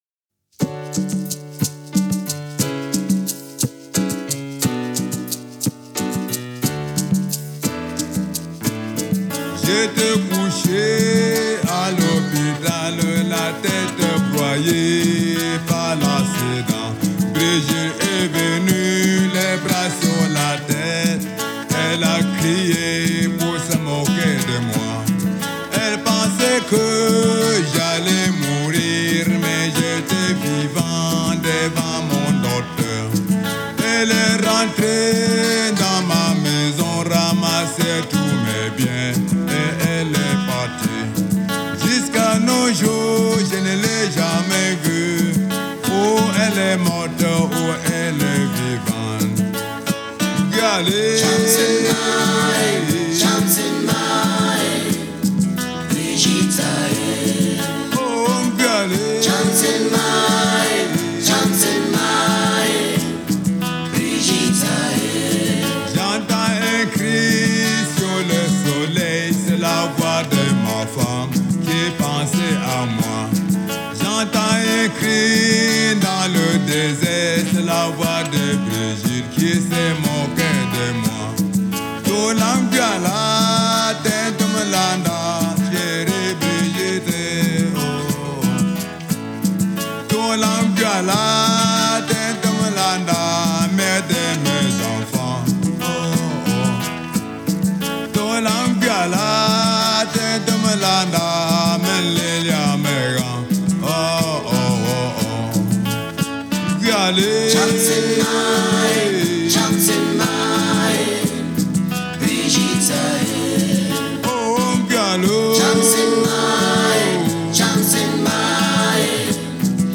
Genre: Africa, World